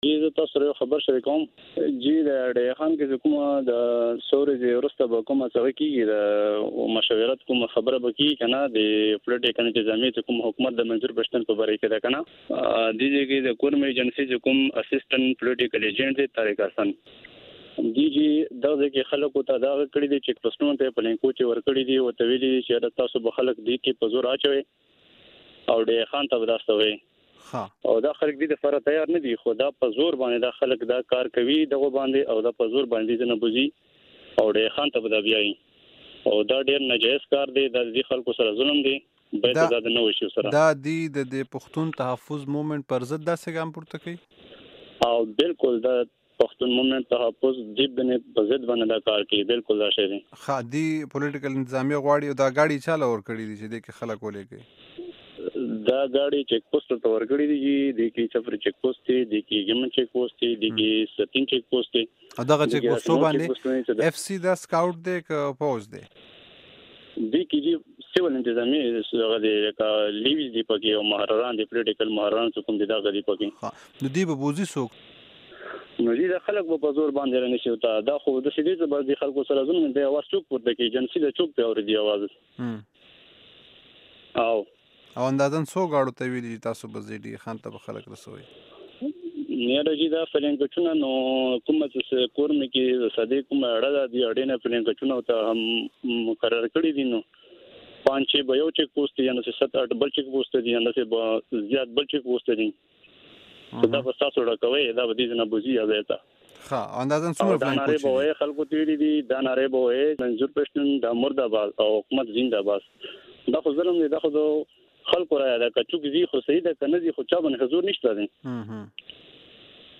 د دغو خبرونو پخلی د کرمې قبایلي سېمې یو ځوان وي او ای ډیوه ته په روانه خپرونه کې د نامې نه ښودلو په شرط څه داسې کړی.